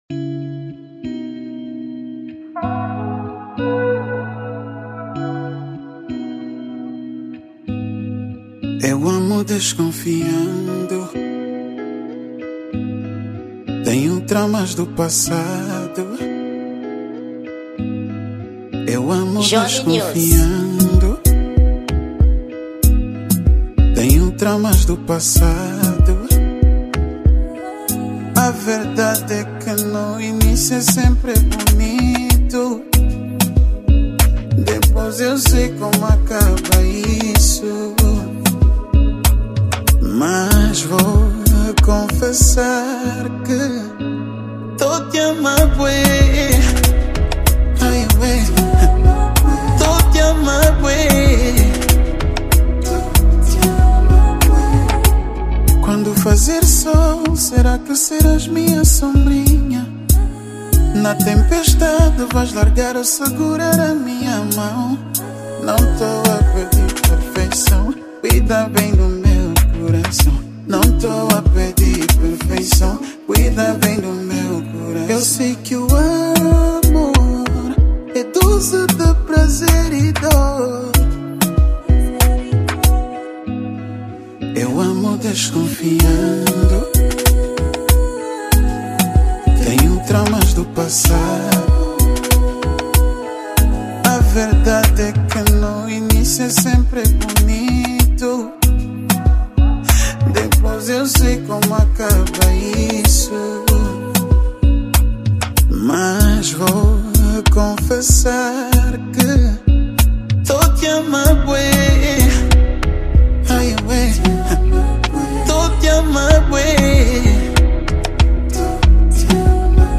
Gênero: Tarraxinha